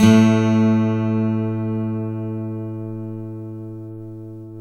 GTR 12STR 02.wav